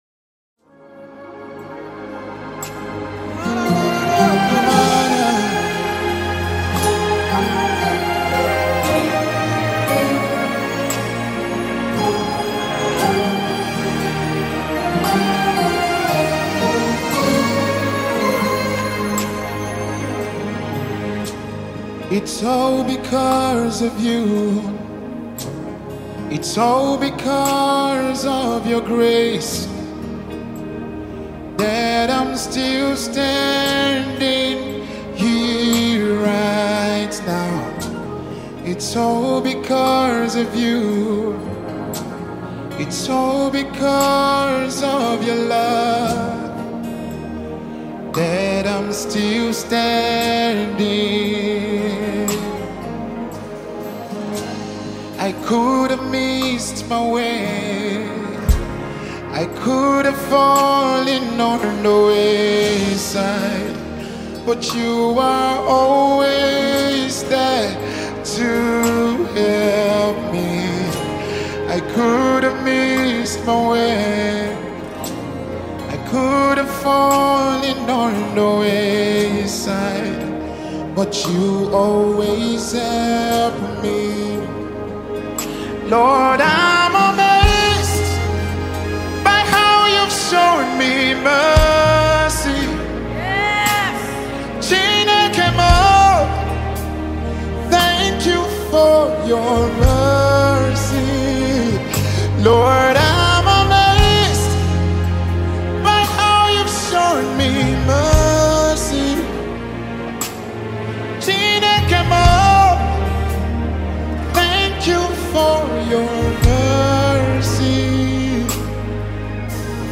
a powerful song of worship and thanksgiving